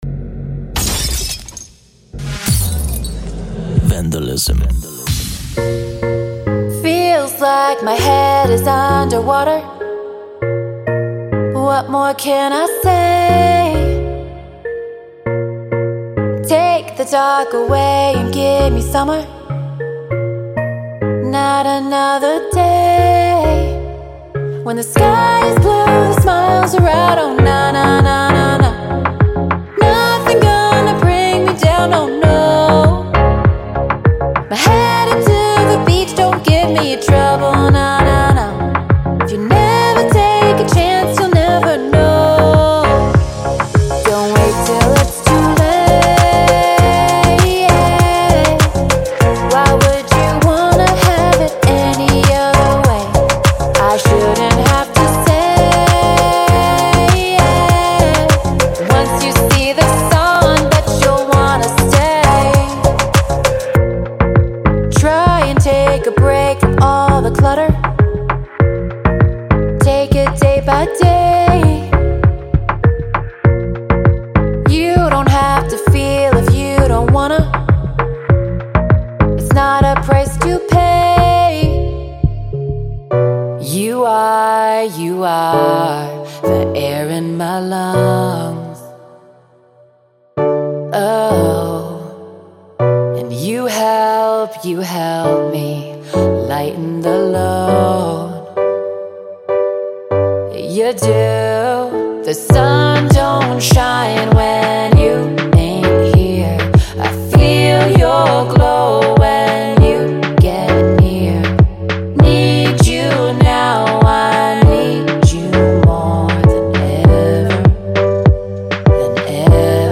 它为您的曲目带来真实，人性化的感觉。
.24x(干式)-人声短语
.04x(湿)-声乐短语
·完全混合和掌握